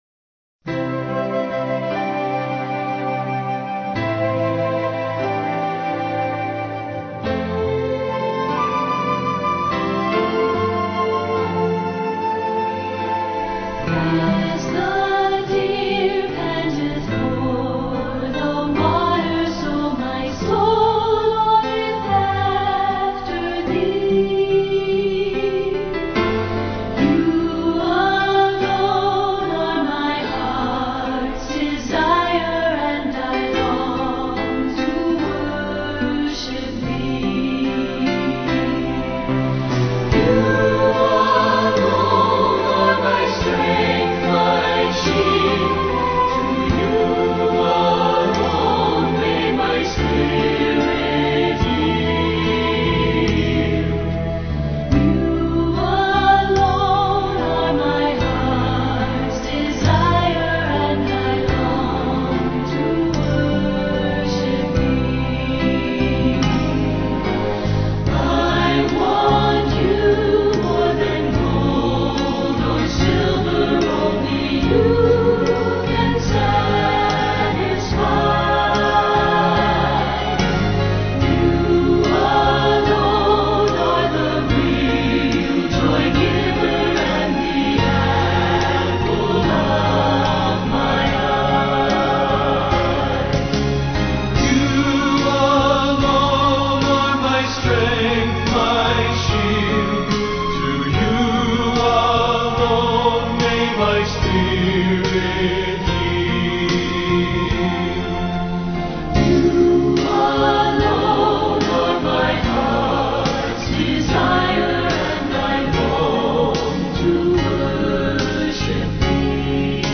Hymn : As The Deer Pants